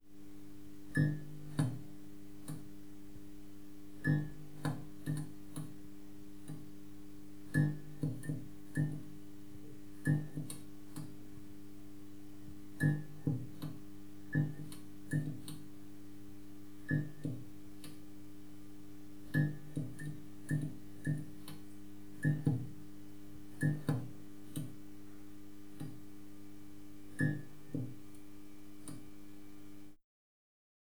neon-light-flicker.wav